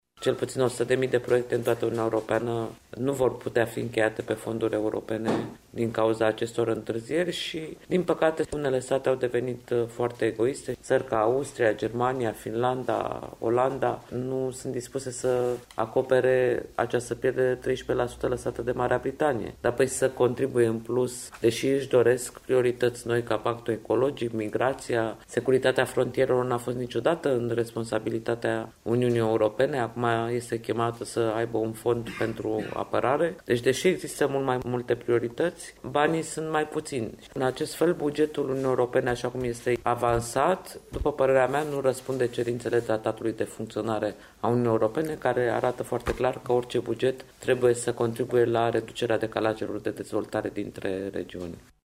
Blocajul în care se află adoptarea bugetului UE face ca cel puțin 100.000 de proiecte din fonduri europene să nu poată fi finalizate, a estimat astăzi la Târgu Mureș eurodeputatul Corina Crețu.